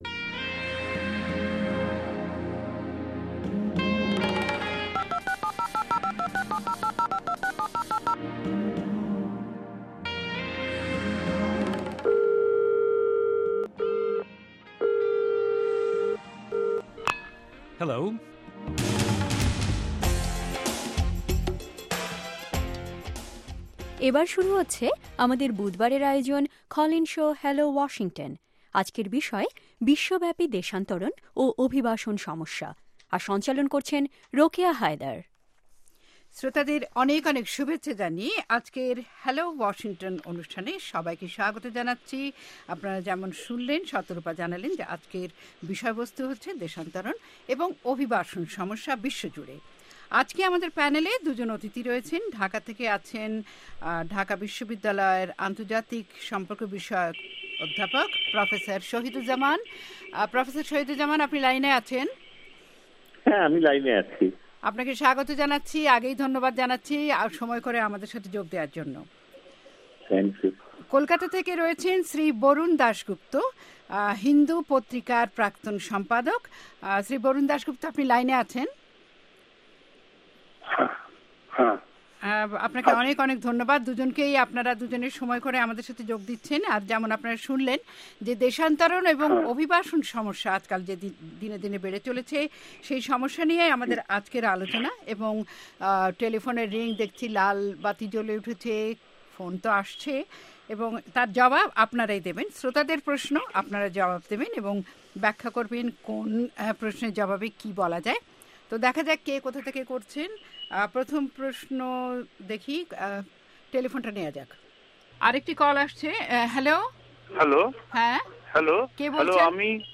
প্রতি বুধবার ভয়েস অব আমেরিকার ওয়াশিংটন ষ্টুডিও থেকে সরাসরি প্রচারিত অনুষ্ঠান হ্যালো ওয়াশিংটনে আজকের